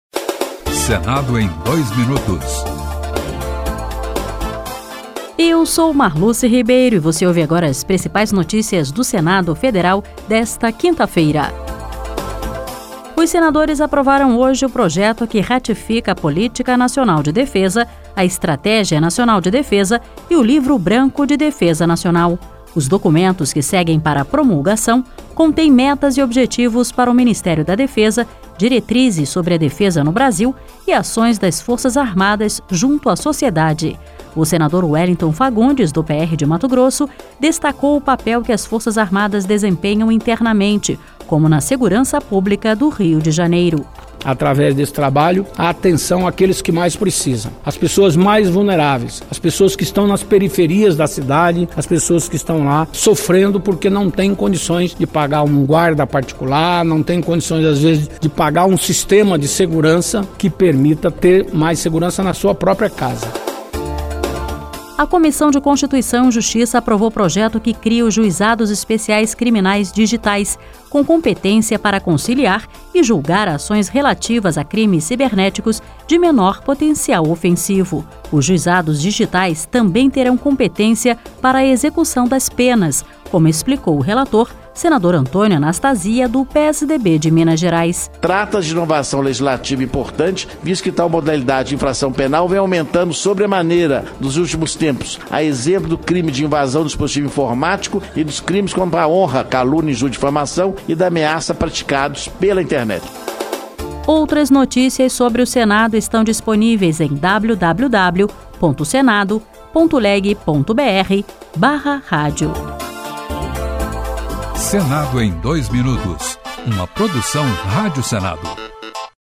Um resumo das principais notícias do Senado